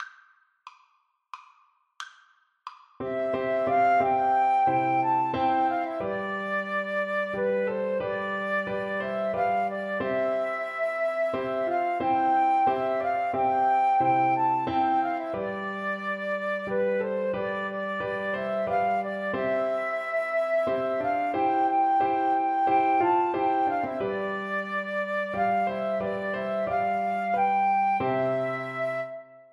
3/4 (View more 3/4 Music)
Christmas (View more Christmas Flute Music)